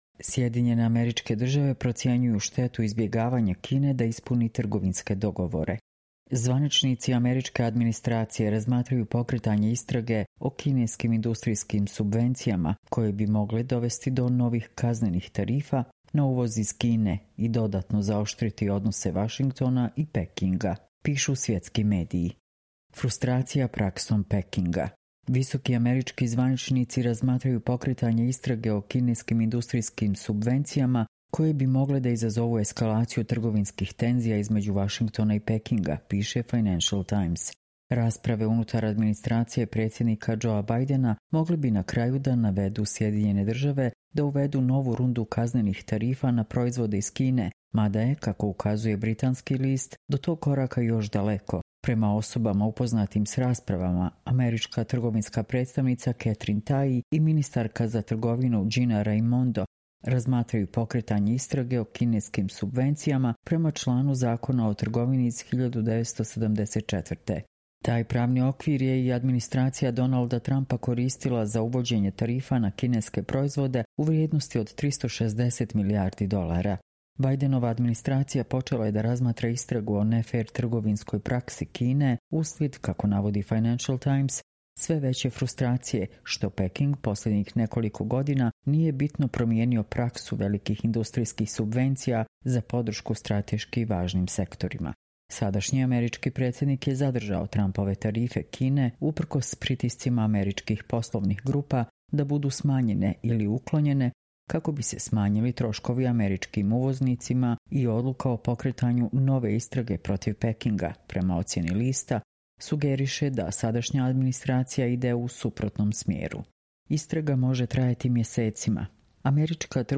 Čitamo vam: SAD procenjuje štetu izbegavanja Kine da ispuni trgovinske dogovore